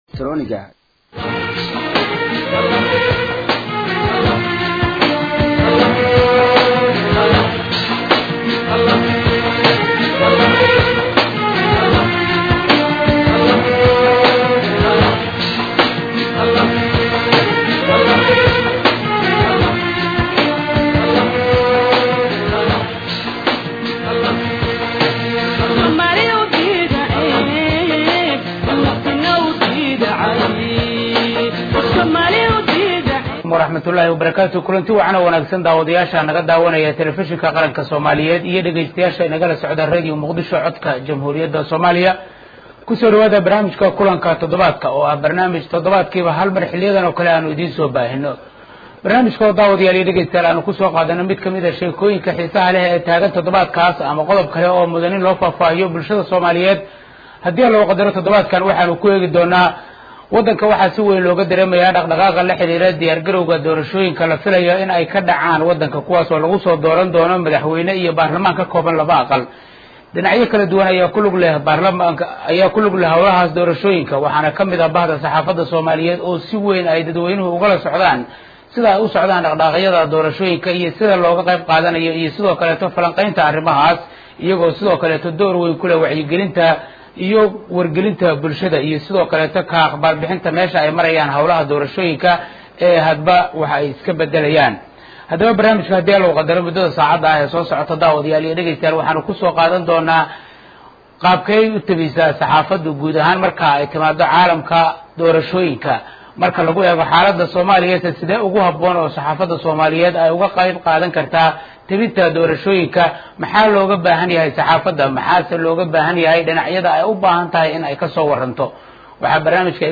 Barnaamijka kulanka todobaadka ee ka baxa Radio Muqdisho iyo Telefishinka Qaranka ayaa looga hadlay kaalinta saxaafadda kaga aadan doorashooyinka dadban